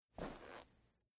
在薄薄的雪地上脚步声－轻－左声道－YS070525.mp3
通用动作/01人物/01移动状态/02雪地/在薄薄的雪地上脚步声－轻－左声道－YS070525.mp3